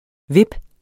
Udtale [ ˈveb ]